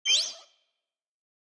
Sfx_creature_seamonkeybaby_hold_01.ogg